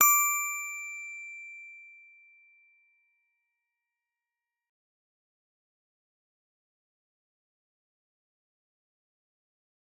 G_Musicbox-D6-f.wav